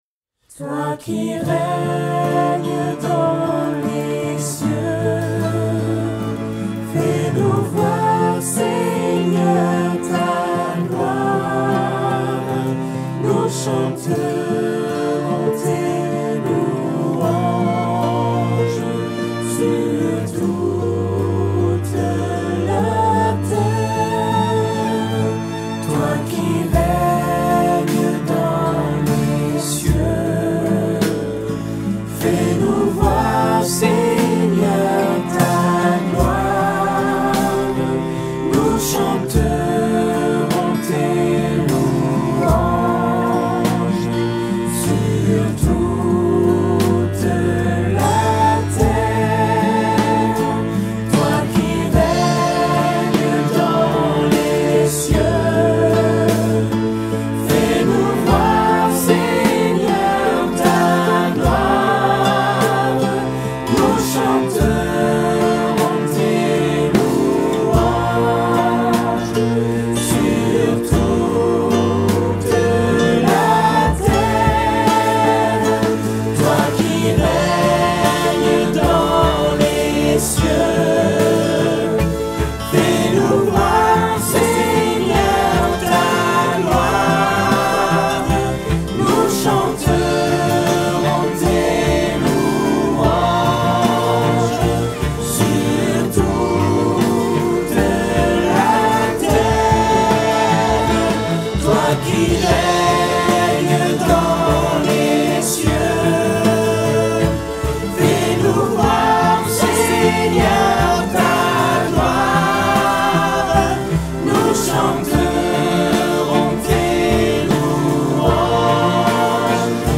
Chants de veillée et de louange